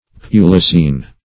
Search Result for " pulicene" : The Collaborative International Dictionary of English v.0.48: Pulicene \Pu"li*cene\, a. [From L. pulex, pulicis, a flea.] Pertaining to, or abounding in, fleas; pulicose.
pulicene.mp3